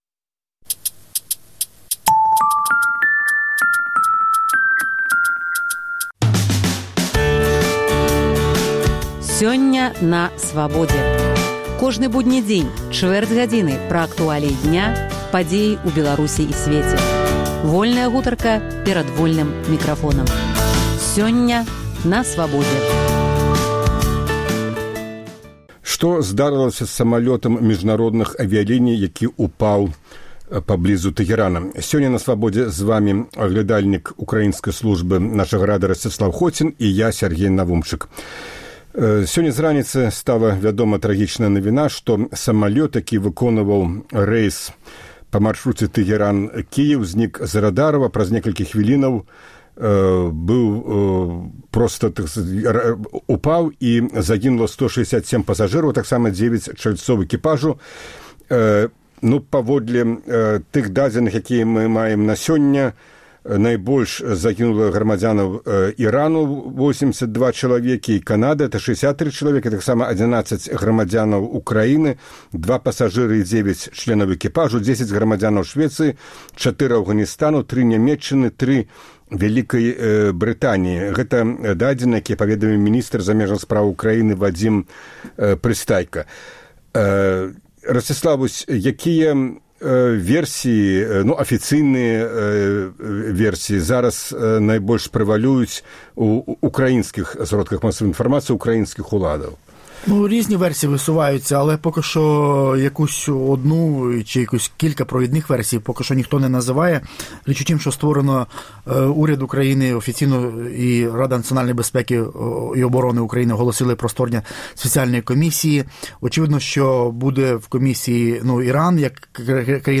Інтэрвію тыдня.